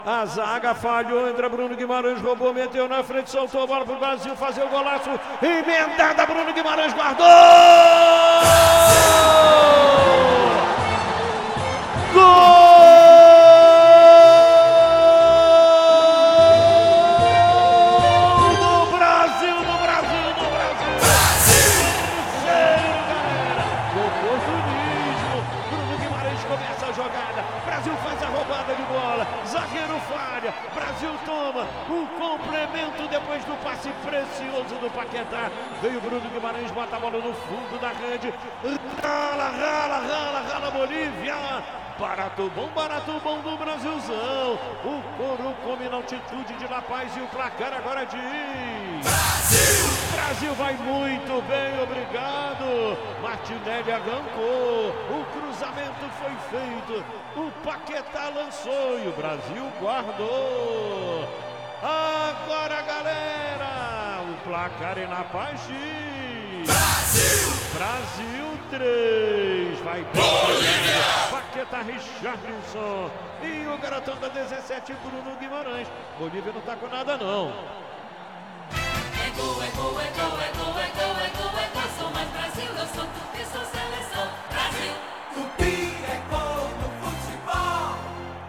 Ouça os gols da vitória do Brasil sobre a Bolívia com a narração de Luiz Penido